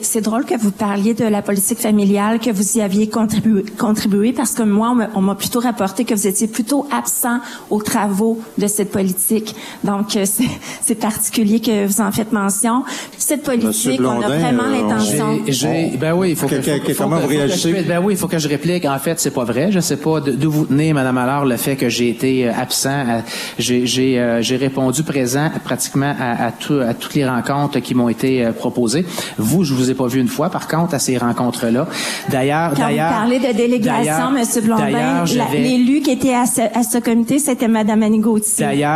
Quelques flèches ont été lancées l’un envers l’autre lors du débat entre les candidats à la mairie de Bécancour qui se tenait mercredi en soirée.